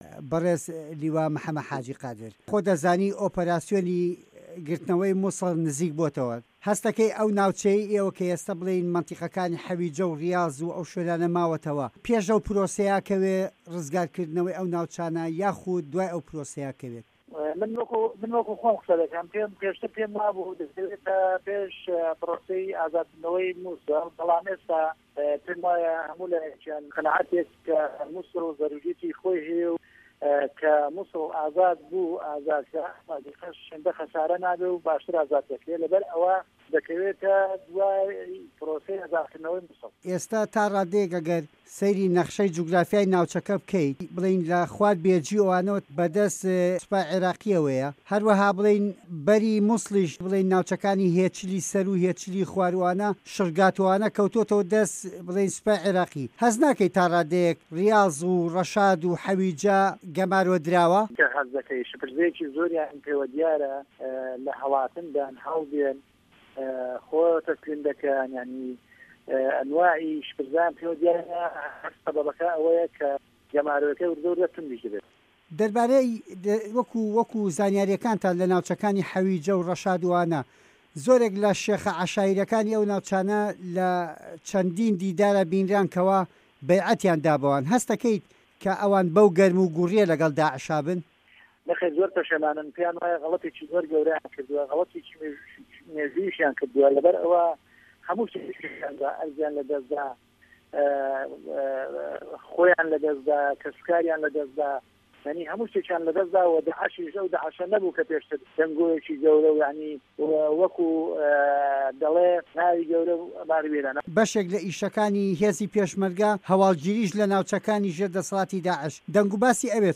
وتووێژ لەگەڵ لیوا قادر حاجی محه‌مه‌د